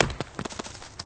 smack_roll_longer.ogg